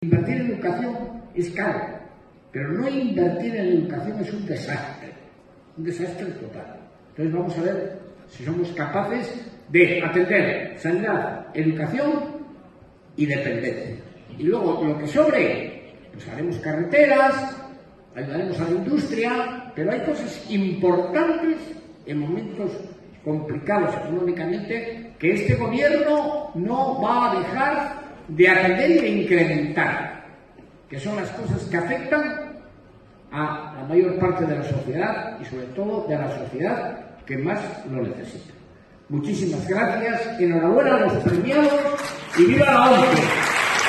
Foto de familia de los premiados junto a las autoridadesLa semana siguiente, el jueves 24 de octubre, fue la ONCE de Cantabria la que rindió homenaje al espíritu solidario de la sociedad montañesa con la entrega de sus galardones autonómicos 2019, en una gala celebrada en un abarrotado Teatro Casyc de Santander y presidida por los máximos responsables de la Comunidad Autónoma, Miguel Ángel Revilla, y del Grupo Social ONCE, Miguel Carballeda.